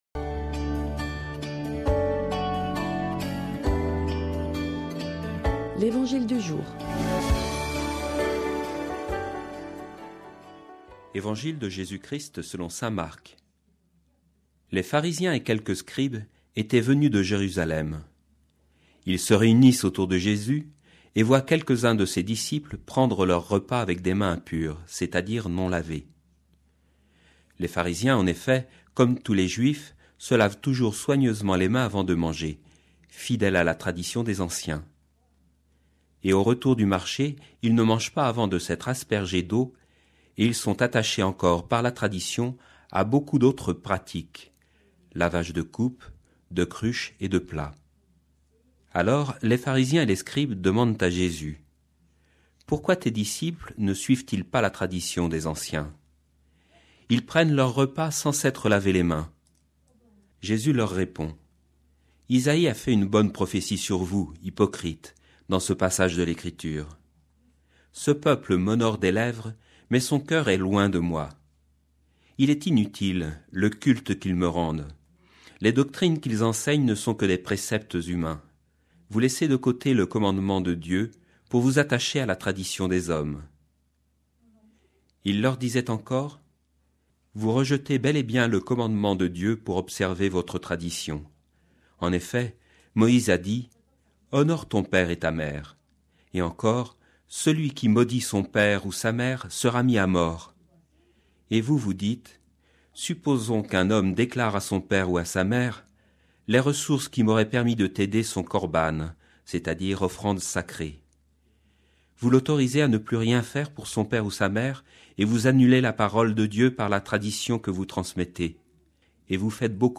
Commentaire d'évangile
Commentaire diffusé sur Fidélité, radio chrétienne de Nantes